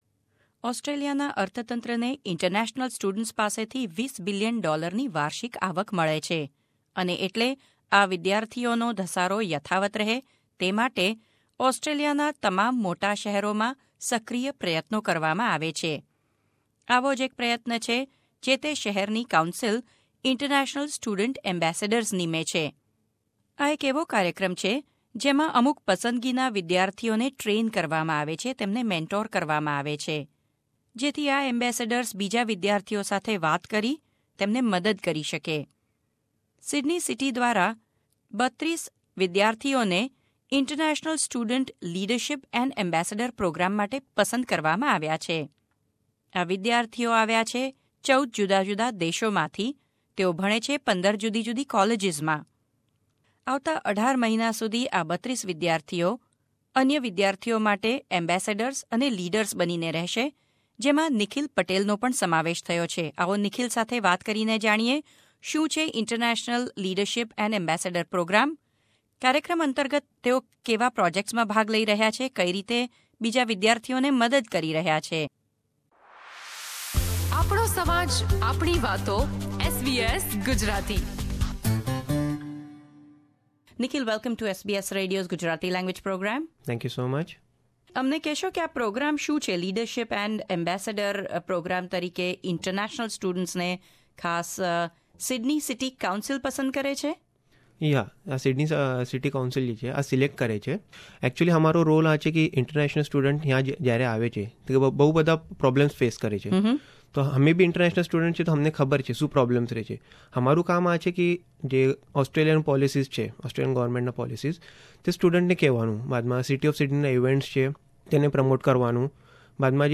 at SBS studios in Artarmon